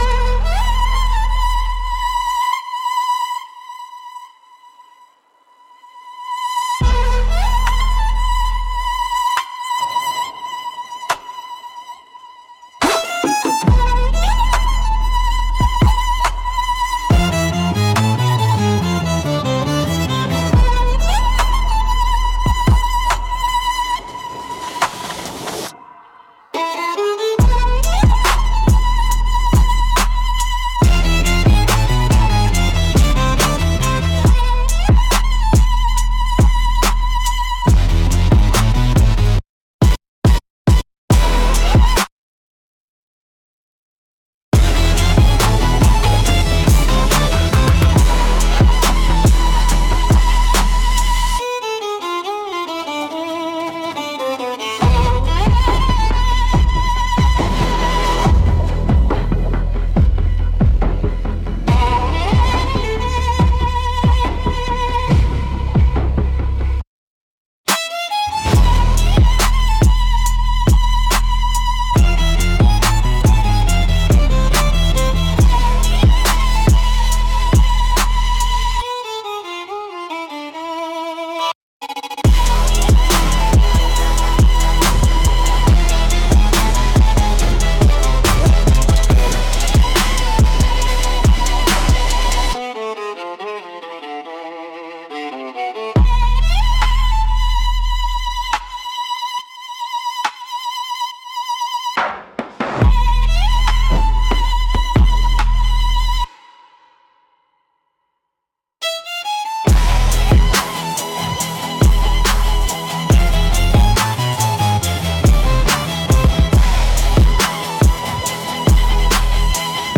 Instrumental - Ashes on 808s